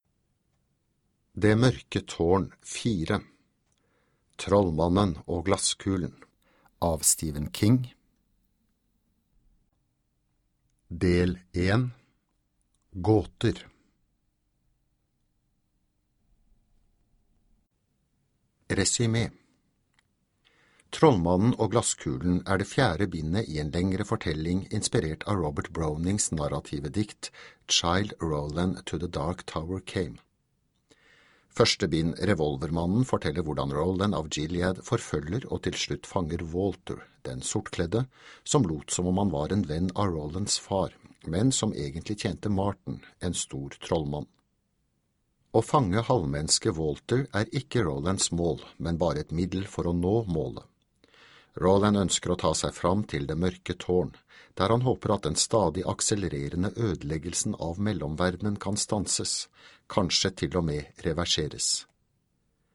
Det mørke tårn IV - Del 1 - Gåter (lydbok) av Stephen King